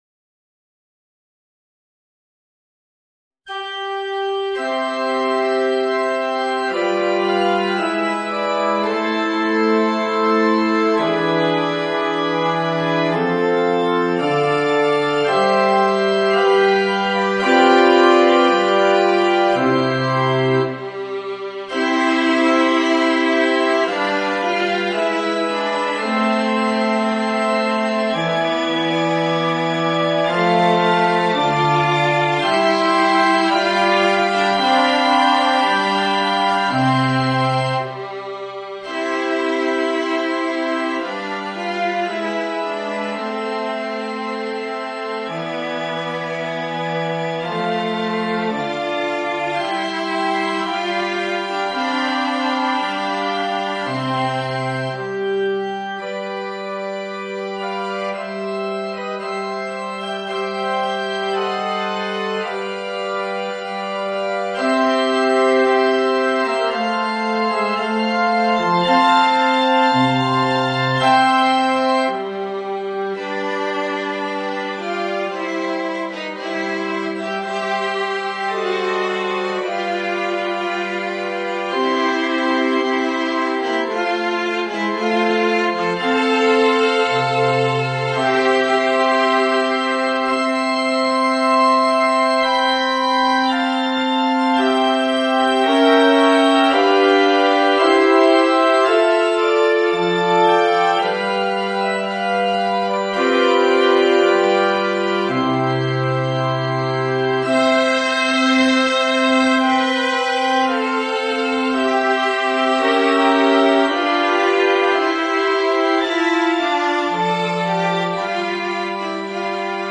Voicing: Viola and Organ